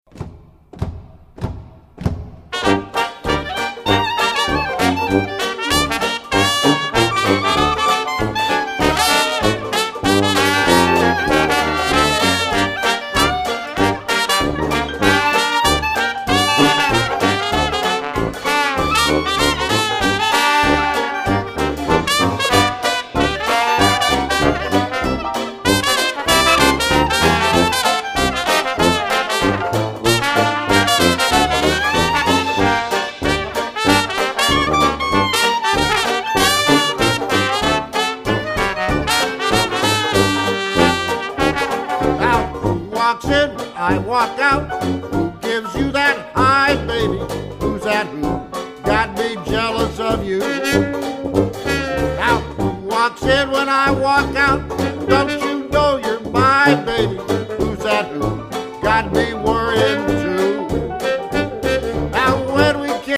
leader/cornet/vocals
tuba